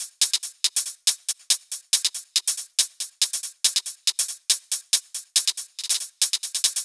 VFH2 140BPM Lectrotrance Kit 7.wav